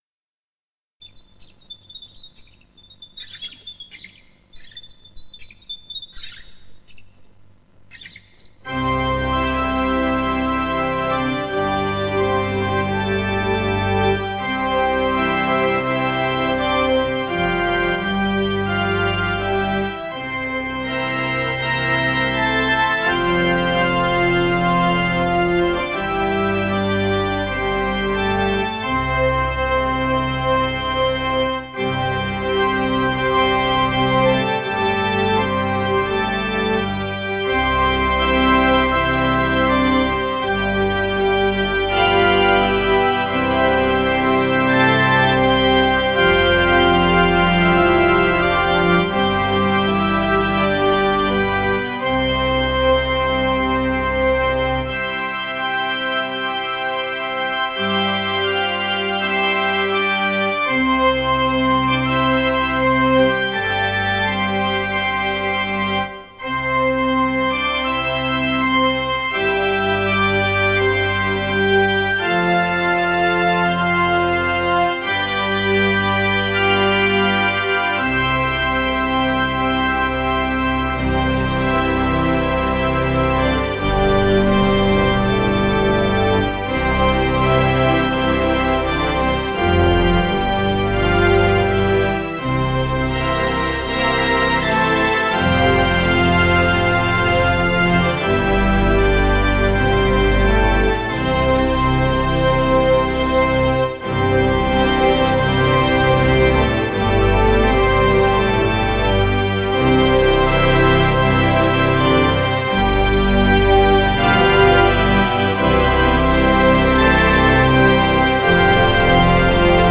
recorded from a Roland Sound Canvas SC-55mkII